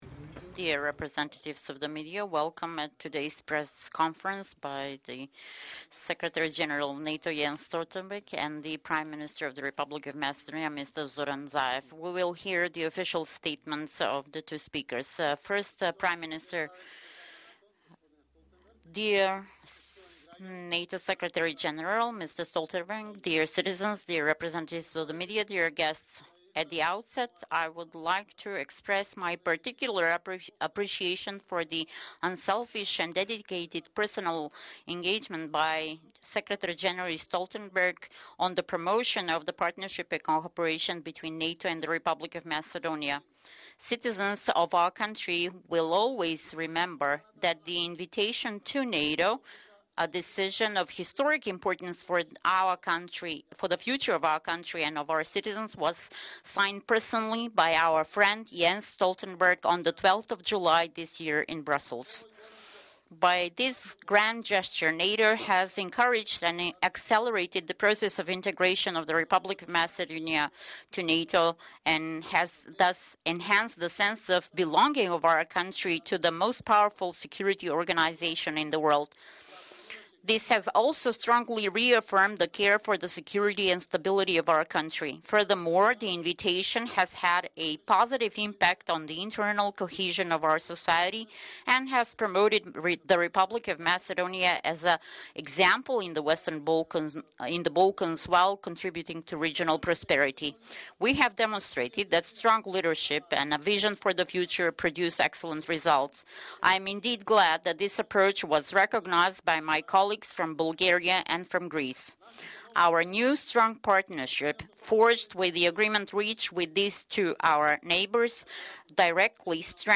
Joint press conference